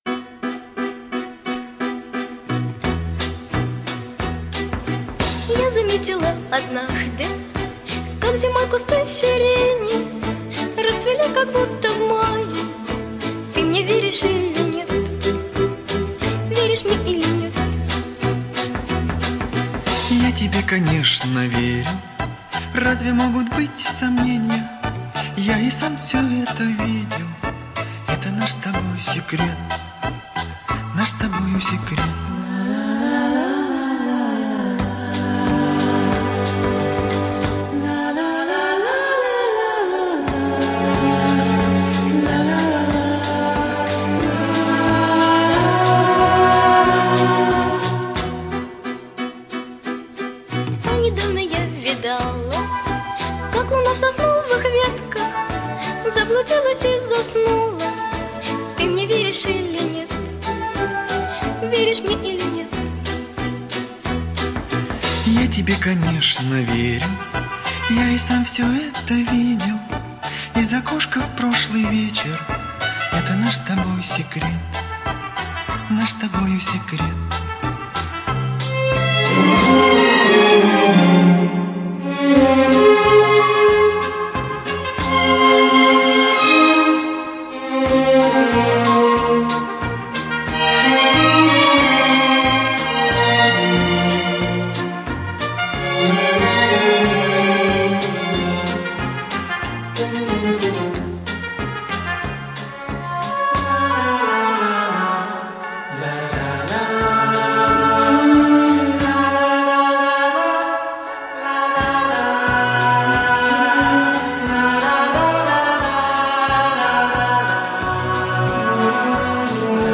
Нежная и трогательная песня